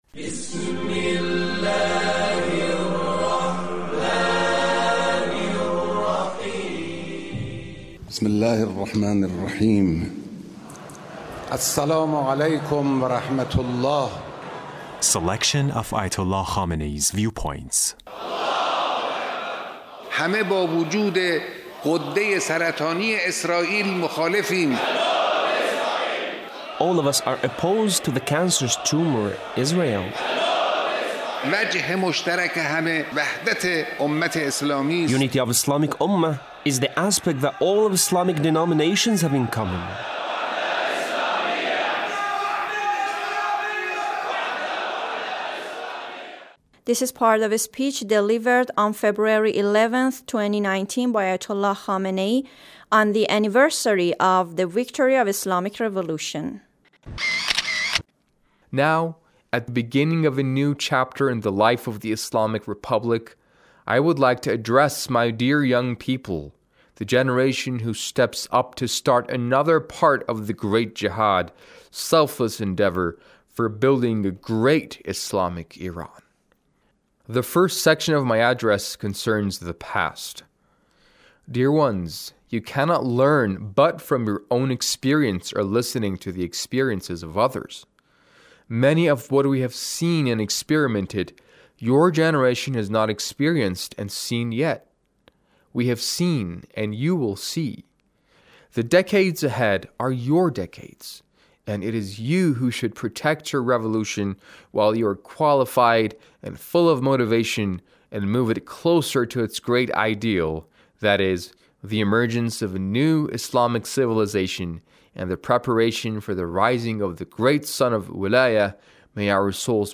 Leader's Speech (11)